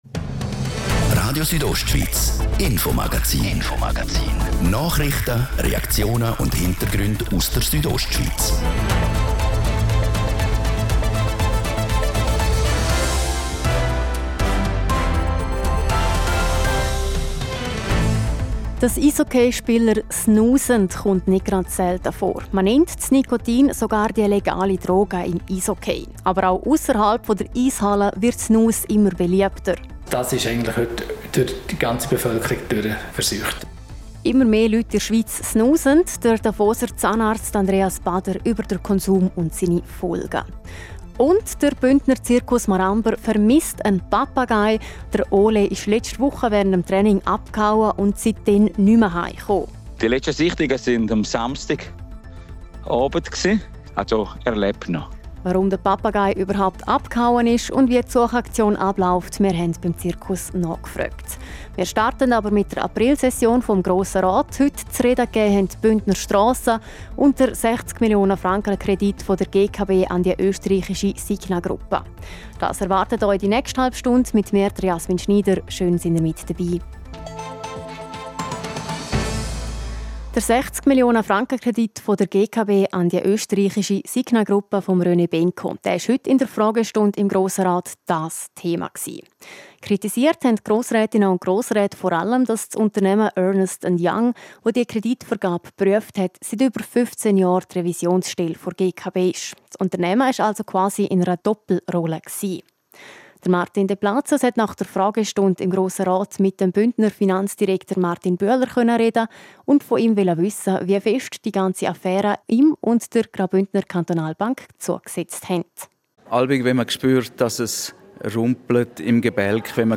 60 Millionen Kredit im Fokus: Bündner Finanzdirektor Martin Bühler äussert sich im Interview über den Kredit der Graubündner Kantonalbank und die Doppelrolle der Revisionsstelle Ernst & Young.
Das Infomagazin am Dienstag, 23. April, mit folgenden Themen: